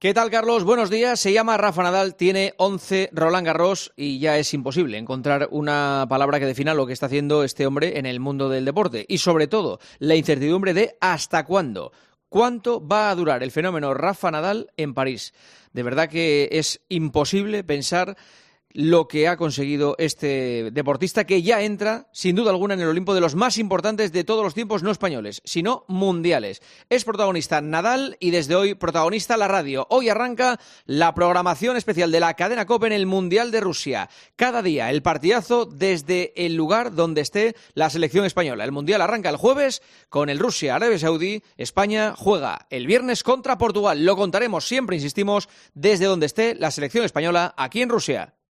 AUDIO: Escucha el comentario del director de 'El Partidazo de COPE', Juanma Castaño, en 'Herrera en COPE'